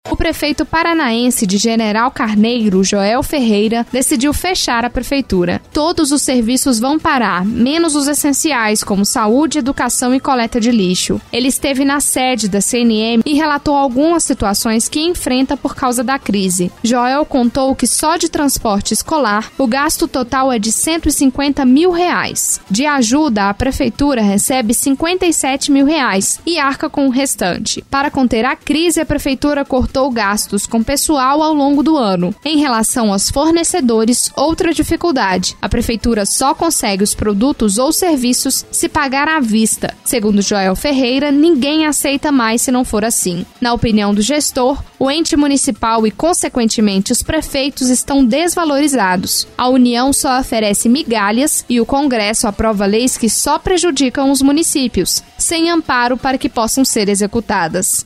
A reportagem da CNN divulgou a situação da palavra do prefeito de General Carneiro, pois foi uma fala que marcou o evento.